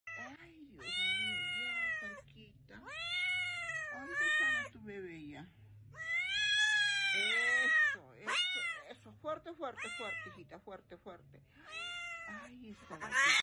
Gatita callejera pariendo a su primer gatito